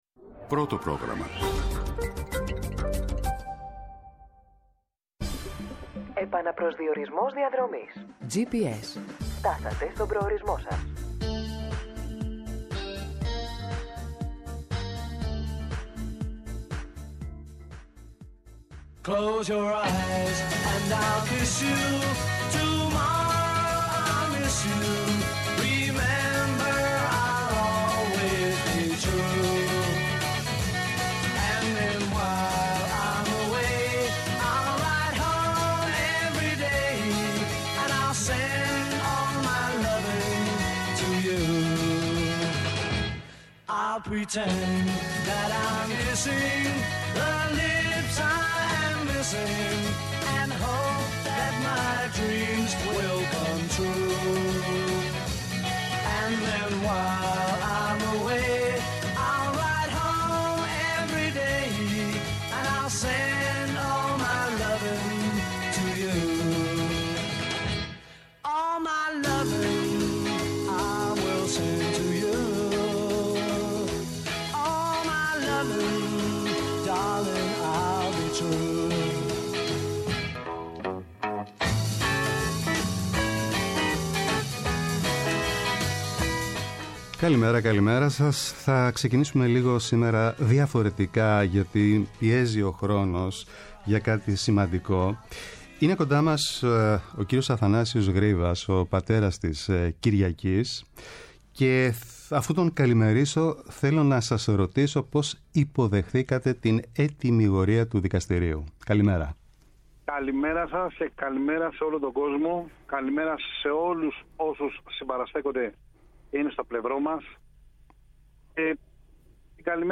-Ο Στράτος Σιμόπουλος, Βουλευτής της ΝΔ, για την διαδικασία και την ψηφοφορία αναφορικά με τη σύσταση προανακριτικής επιτροπής
-Ο Βασίλης Κόκκαλης, Βουλευτής ΣΥΡΙΖΑ, για την απόρριψη της προανακριτικής για τον ΟΠΕΚΕΠΕ
ΕΡΤNEWS RADIO